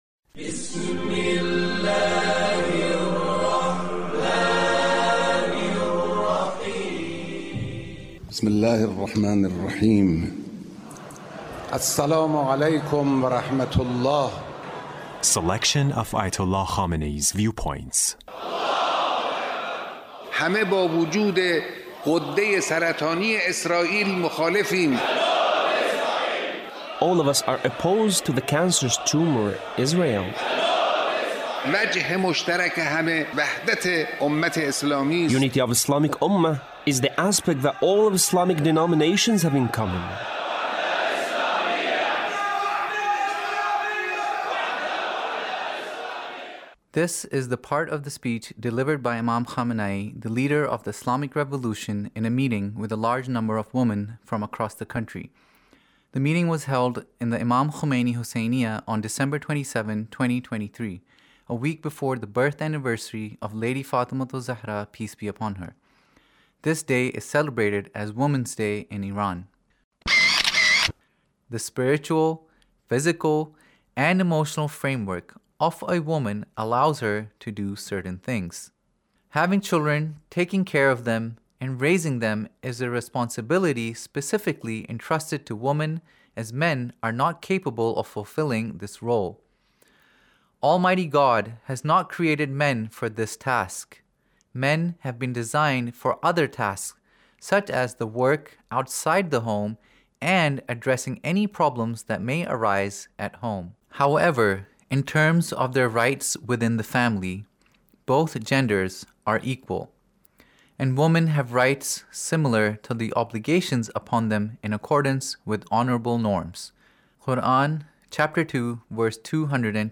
Leader's Speech in a meeting with Ladies on Women's Day